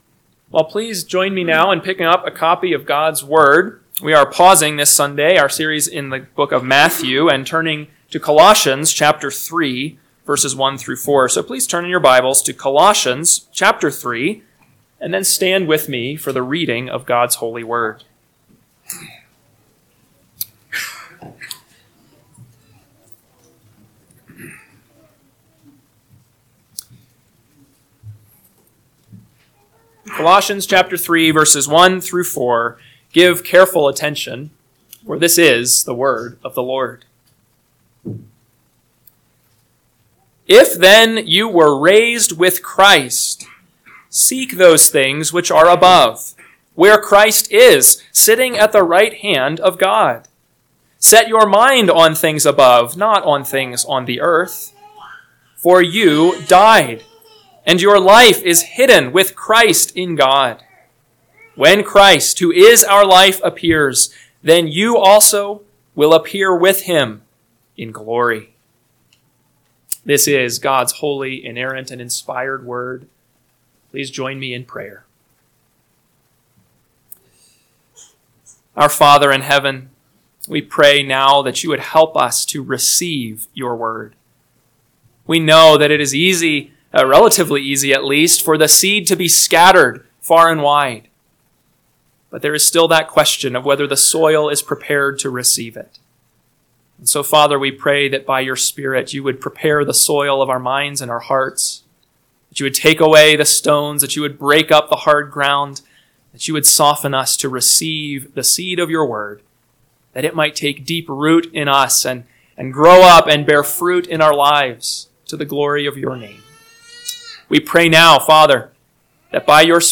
AM Sermon – 4/20/2025 – Colossians 3:1-4 – Northwoods Sermons